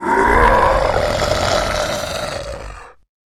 troll_warrior_die.wav